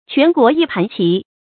全国一盘棋 quán guó yī pán qí
全国一盘棋发音